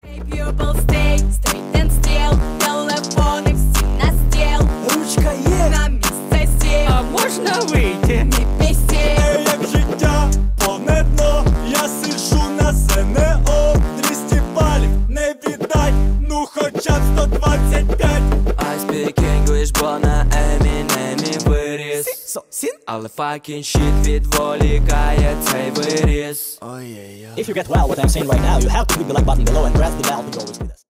Поп Музыка
пародия # весёлые